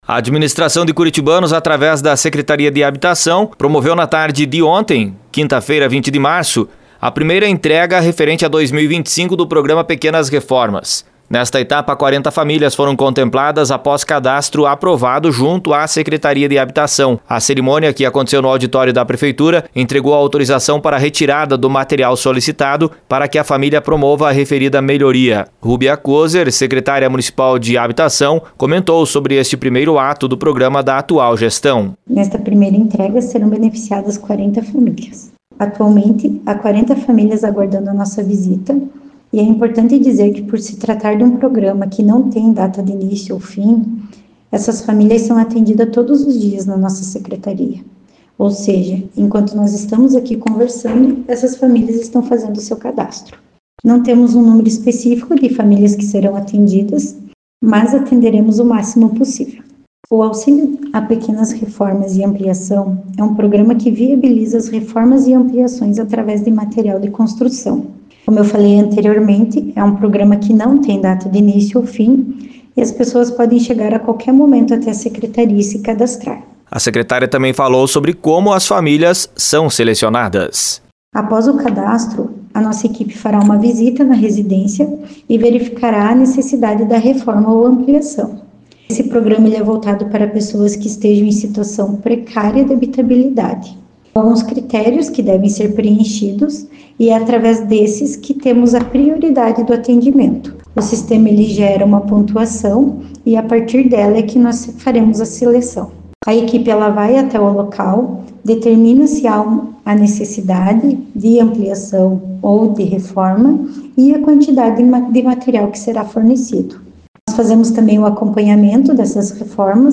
Informações com repórter